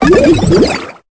Cri de Symbios dans Pokémon Épée et Bouclier.